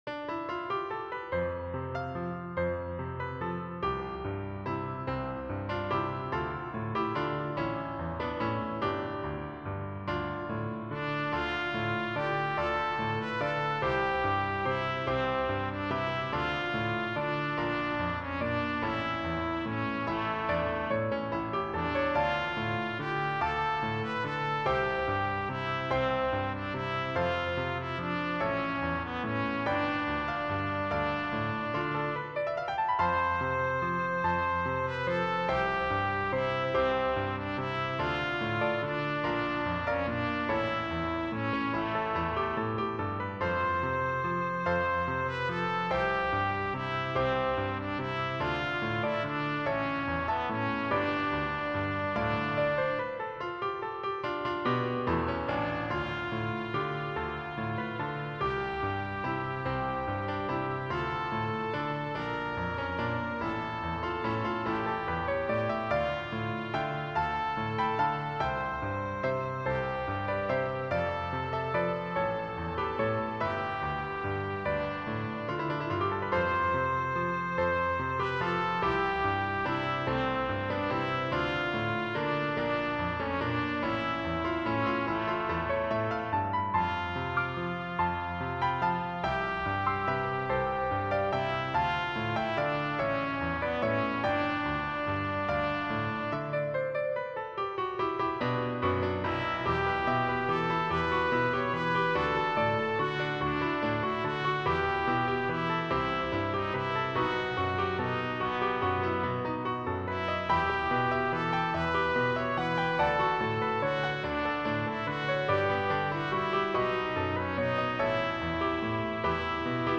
Trumpet Solos
These are for trumpet solo with piano accompaniment.
The recording is in D minor.
Greensleeves-Tpt-D-dorian-full.mp3